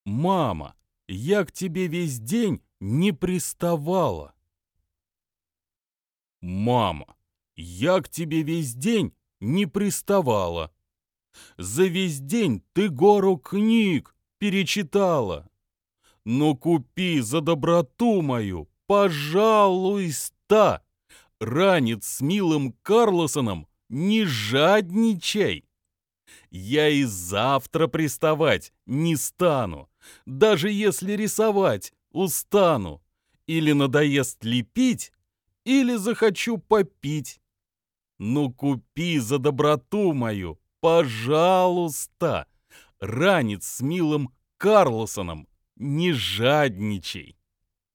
Аудиокнига Там сугроб на дорожке. Стихи для детей | Библиотека аудиокниг